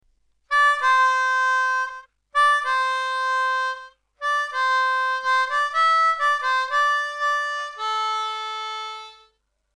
Lee Oskar Melody Maker in G
End section